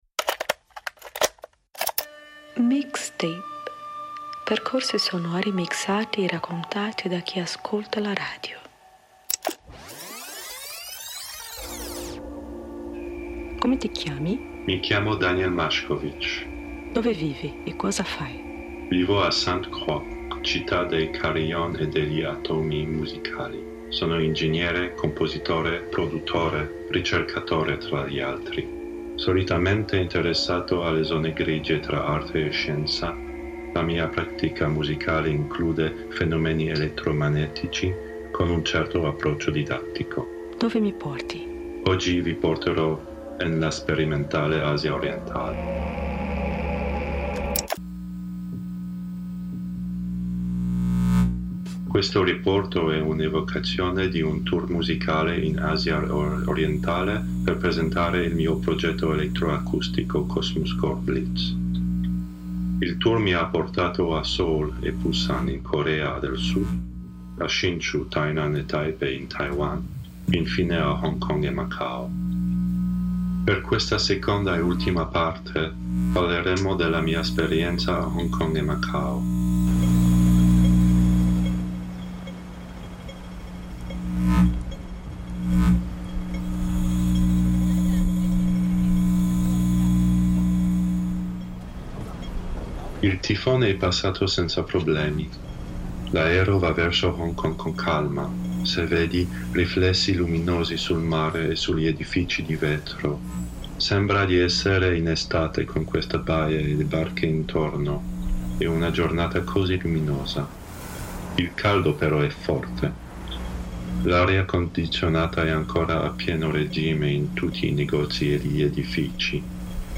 Seconda tappa: Hong Kong a Macao.
Field recordings (Hong Kong)
Field Recordings (Macau)
Improvisation (Live)
Percorsi sonori mixati e raccontati da chi ascolta la radio